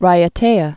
(rīə-tāə)